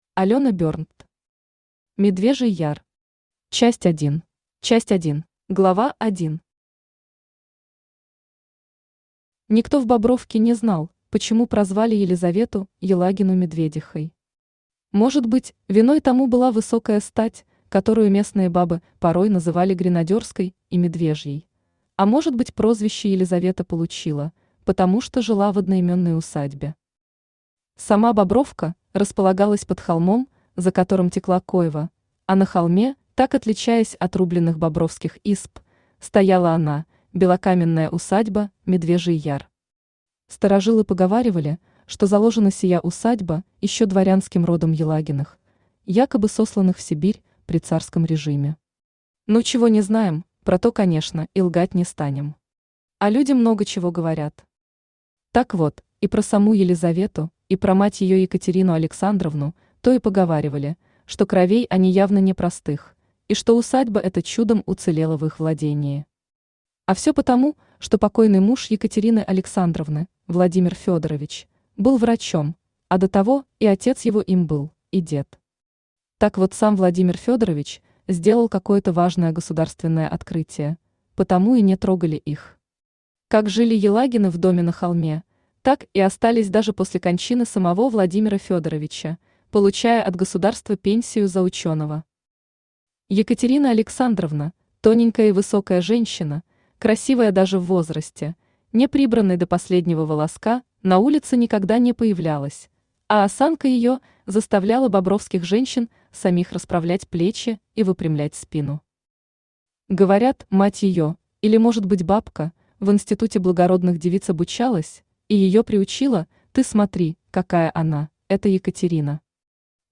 Аудиокнига Медвежий Яр. Часть 1 | Библиотека аудиокниг
Часть 1 Автор Алёна Берндт Читает аудиокнигу Авточтец ЛитРес.